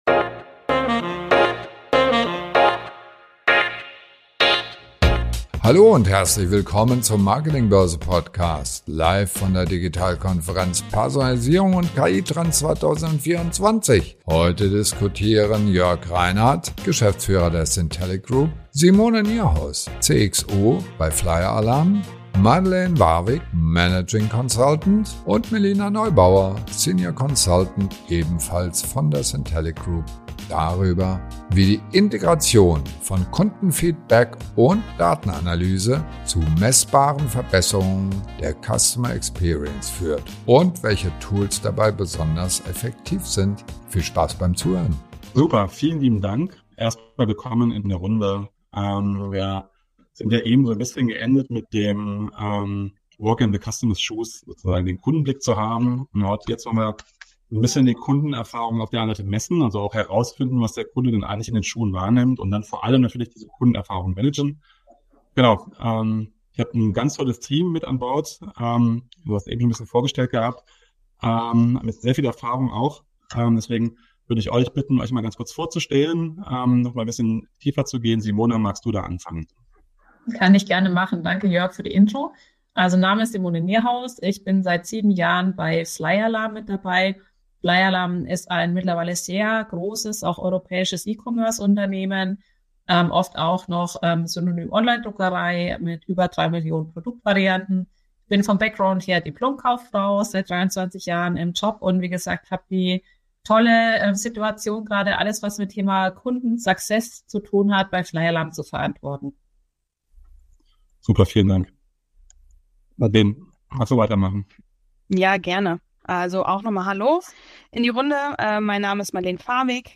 Roundtable